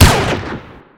fire_railgun.ogg